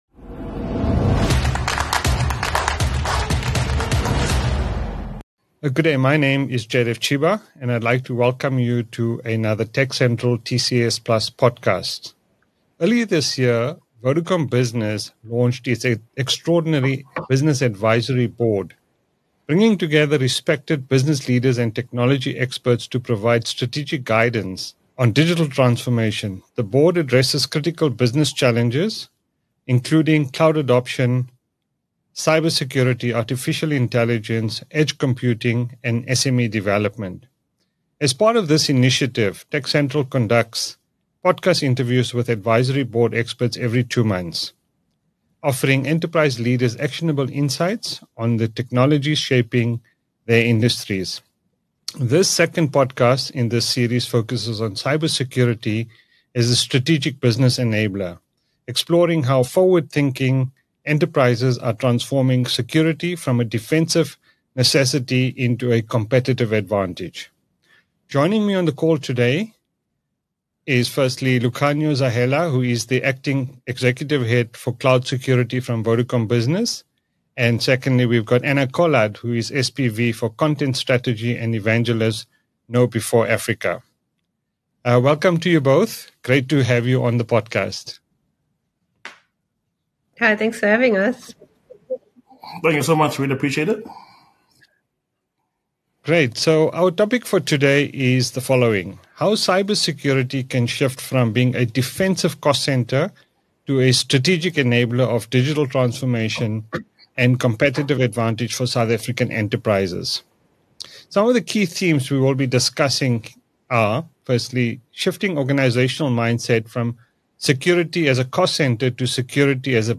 podcast discussion